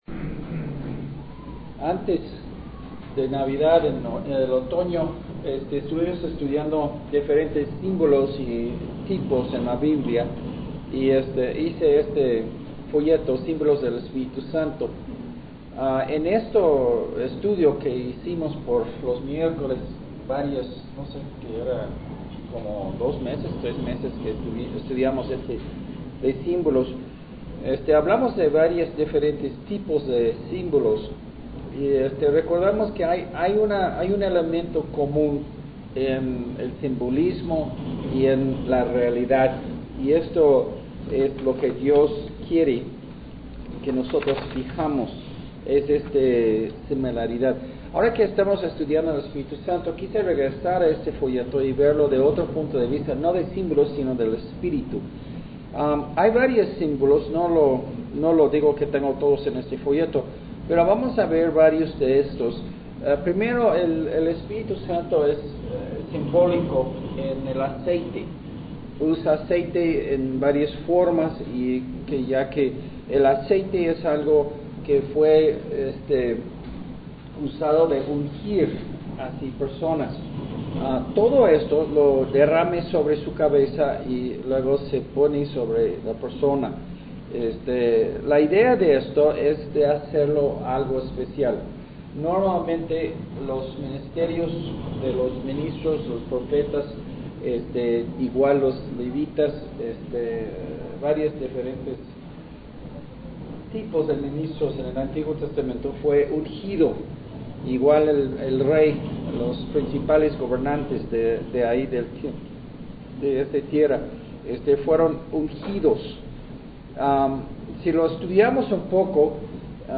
eb05 Los simbolos del Espíritu Santo Sermón en Audio